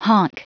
Prononciation du mot honk en anglais (fichier audio)
Prononciation du mot : honk